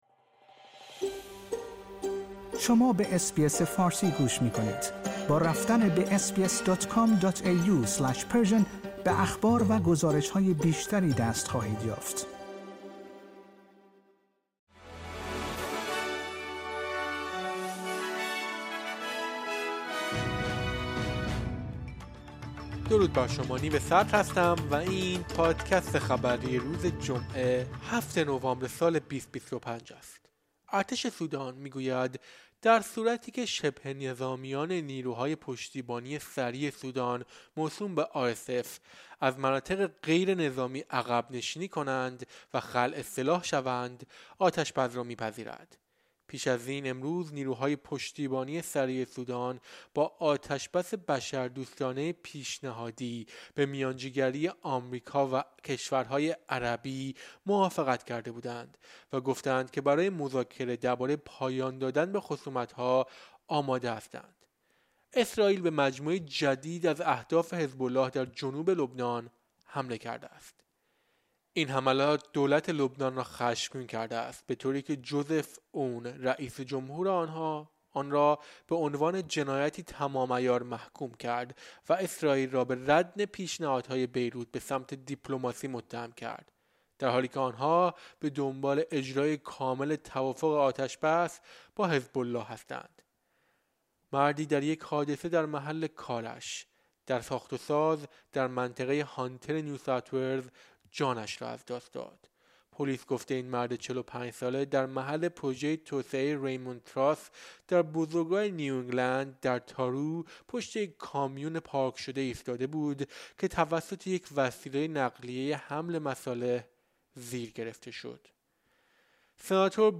در این پادکست خبری مهمترین اخبار روز جمعه ۷ نوامبر ارائه شده است.